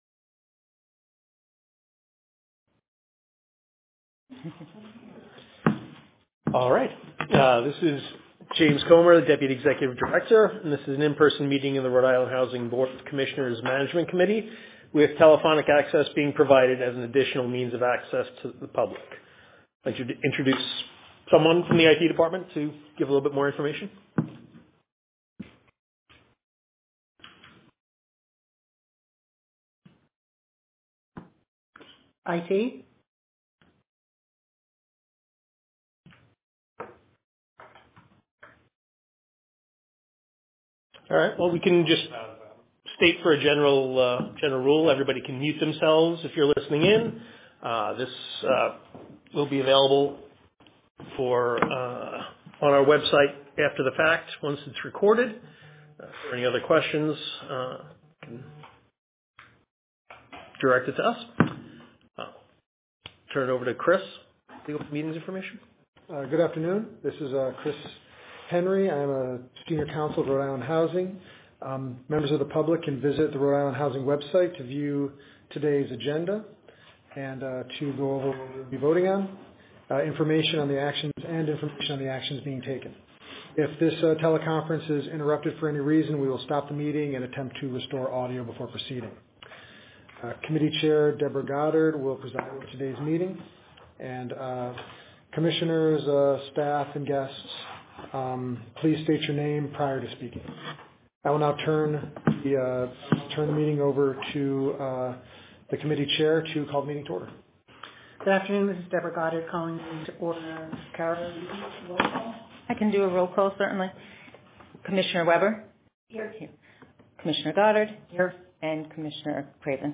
Management Committee Meeting Recording - Monday, December 8th, 2025